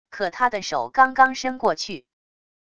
可他的手刚刚伸过去wav音频生成系统WAV Audio Player